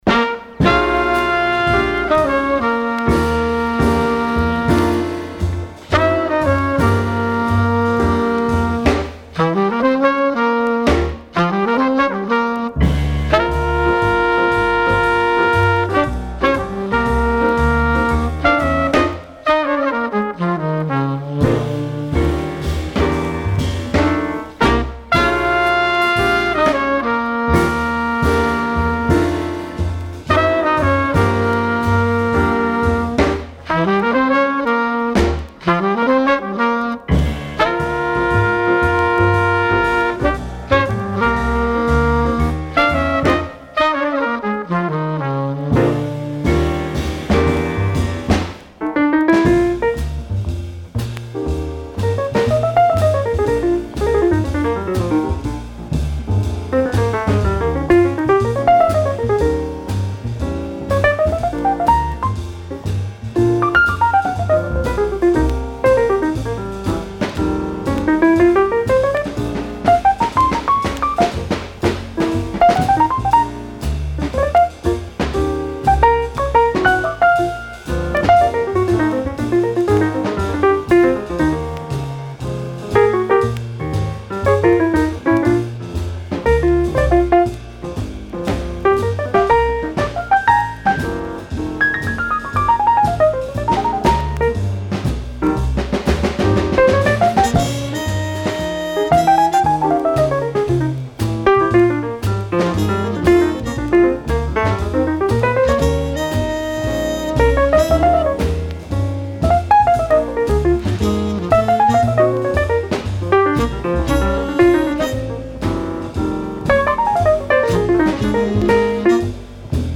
Original 1959 mono pressing
Recorded November 17, 1958 at the Nola Penthouse NYC